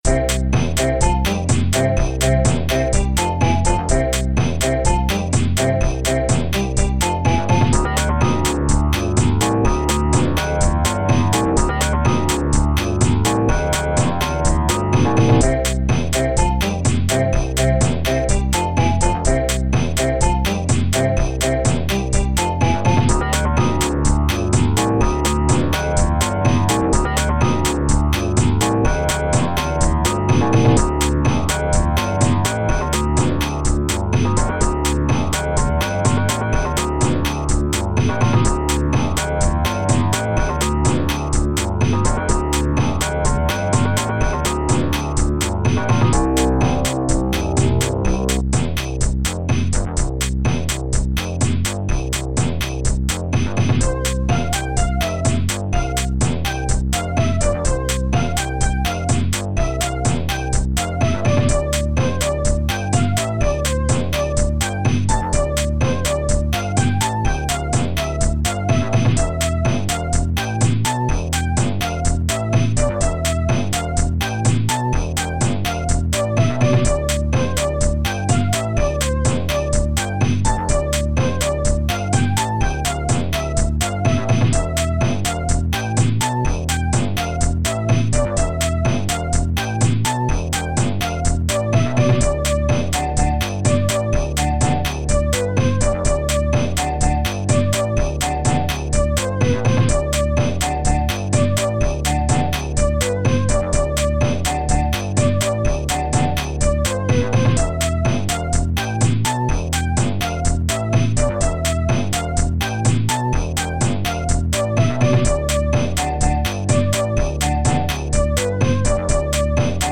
As always, the MOD files have been saved as MP3 for easy listening.
I immediately got curious and created these five tunes in it.
The first four tunes all used ST-01 samples only while the fifth one used samples from both ST-01 and ST-02.